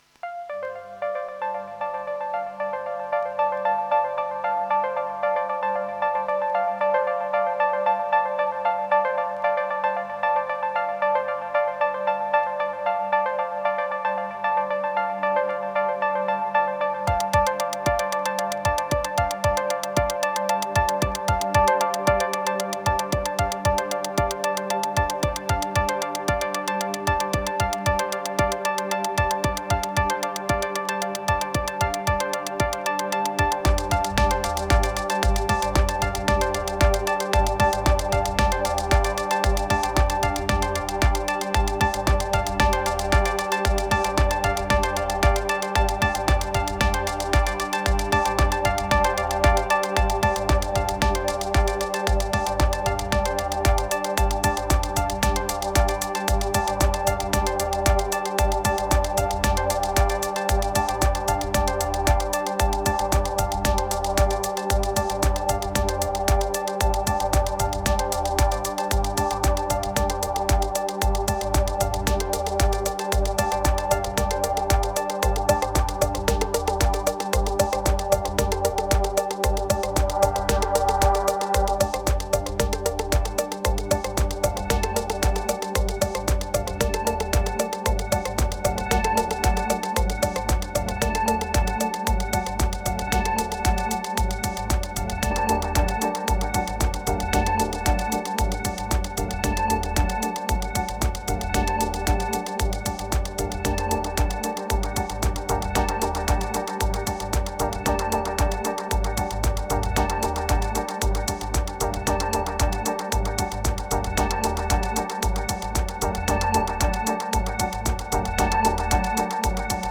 A collection of ambient beat tracks.
1017📈 - 73%🤔 - 59BPM🔊 - 2025-03-20📅 - 271🌟
Easy. Laidback.
Bad mastering ?
Electro Seldom Abstract Lame Attic Drift Surface Mor